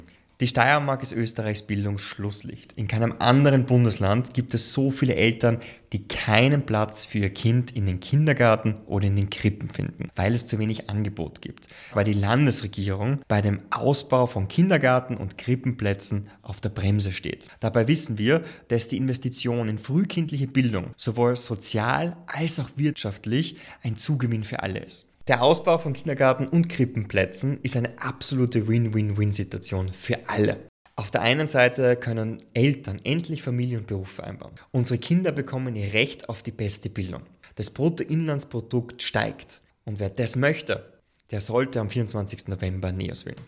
O-Ton Niko Swatek Ausbauturbo: